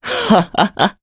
Crow-Vox_Happy3_jp_b.wav